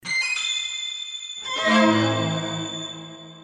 Clannad Horror Sound